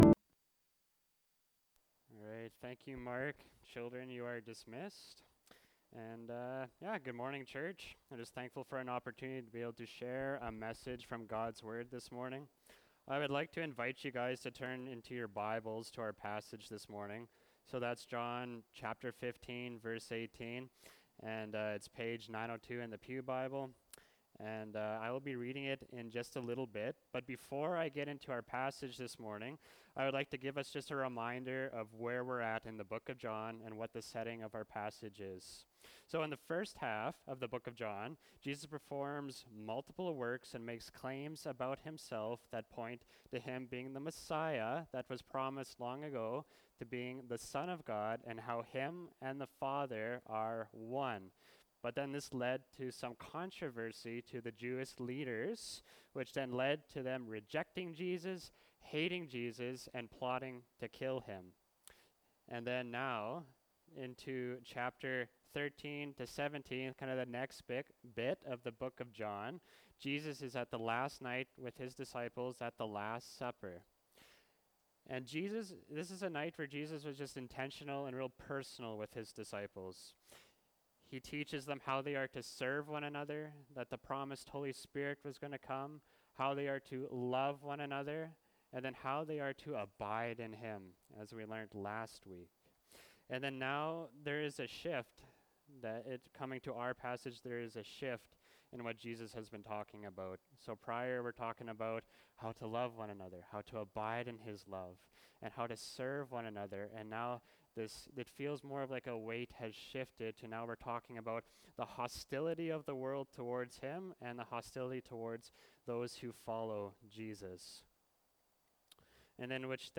Mar 03, 2024 Do Not Be Surprised (John 15:18-16:4a) MP3 SUBSCRIBE on iTunes(Podcast) Notes Discussion Sermons in this Series This sermon was recorded at Grace Church - Salmon Arm and preached in both Salmon Arm and Enderby.